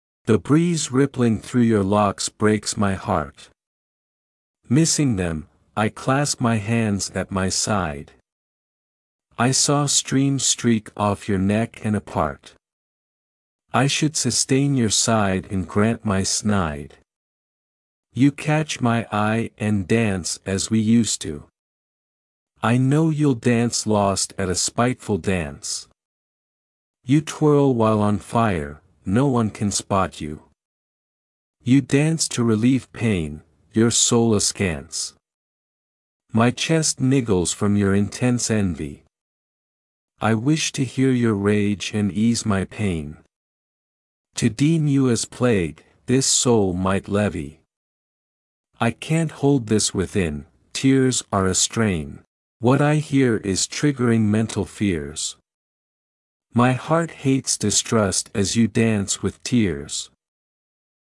It has a softness during the second part. The emotion of jealousy you write about does sting.